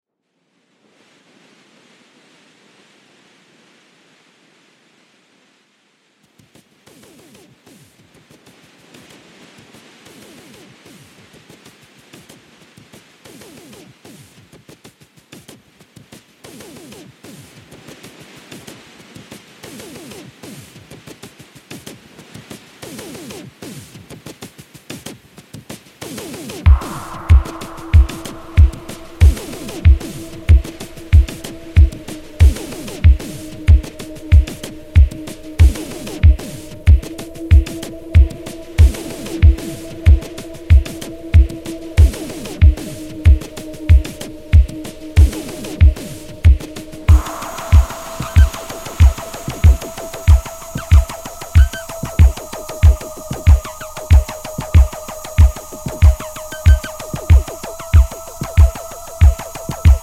synth-wave
left field club remixes
Electro Electronix